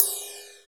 FLANGERIDEF.wav